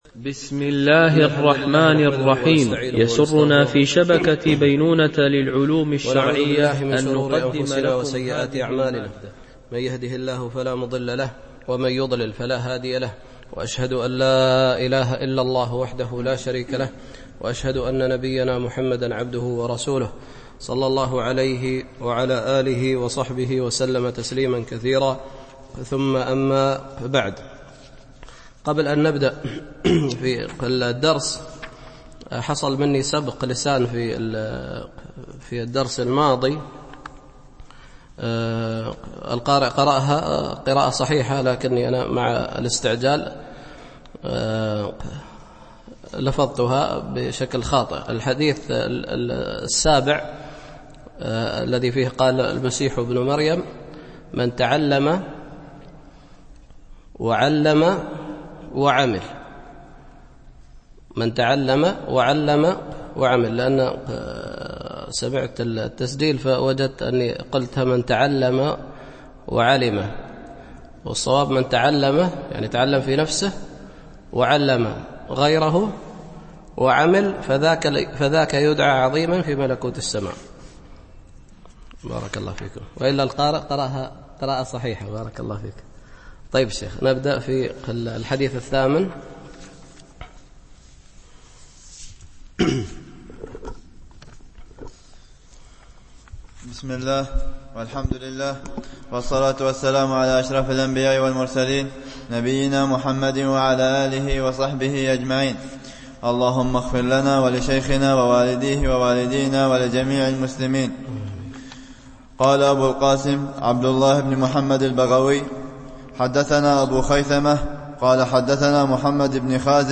شرح كتاب العلم لأبي خيثمة ـ الدرس 3 (الأثر 8 - 11)